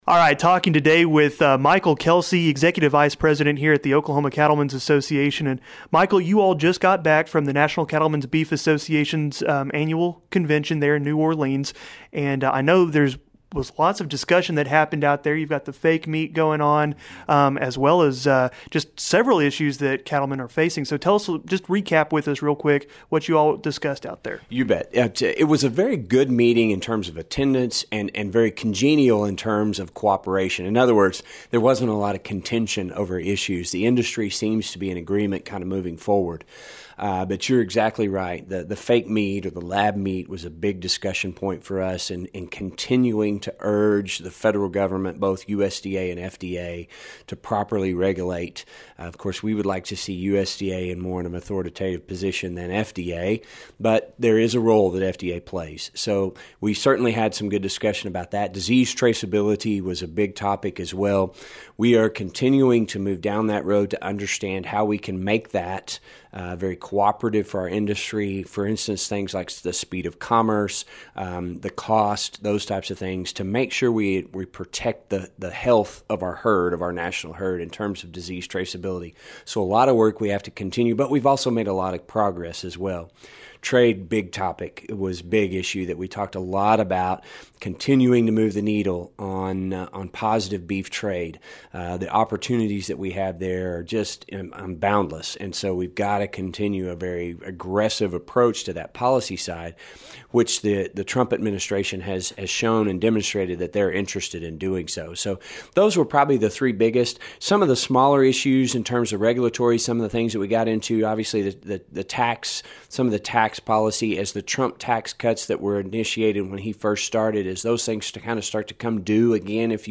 You can listen to their complete conversation by clicking or tapping the LISTEN BAR below at the bottom of the page.